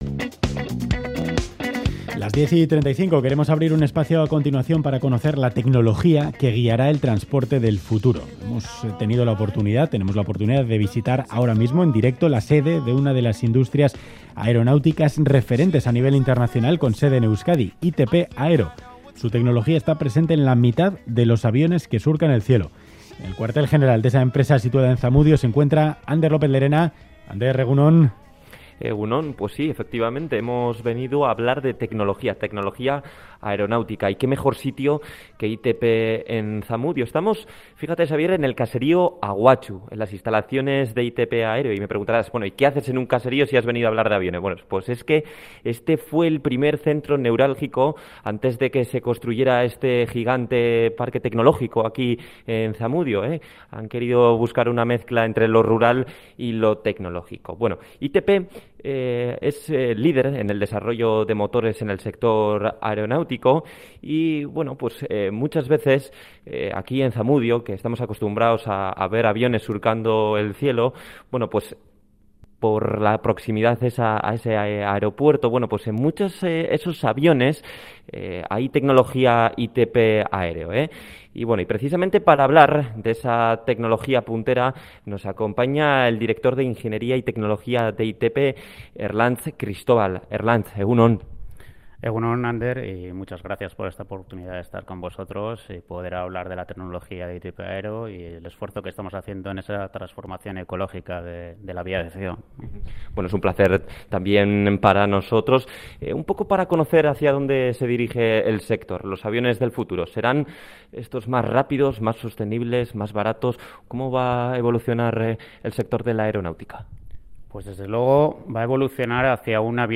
Radio Euskadi visita la sede de una de las industrias aeronáuticas referentes a nivel internacional con sede en Euskadi: ITP Aero. Su tecnología está presente en la mitad de los aviones que surcan el cielo.